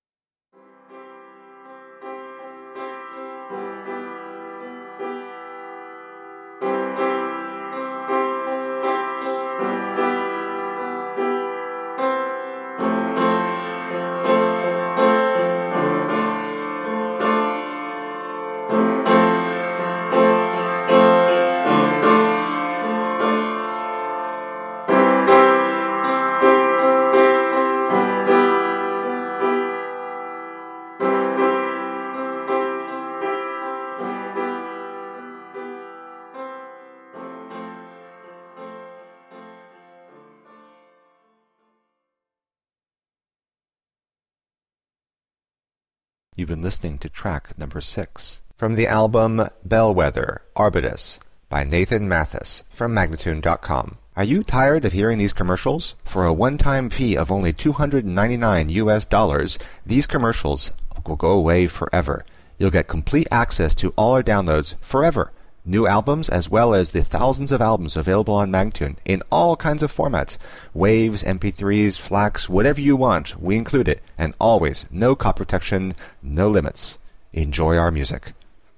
Melody-driven indie-folk.
Tagged as: Alt Rock, Folk-Rock, Chillout, Indie Rock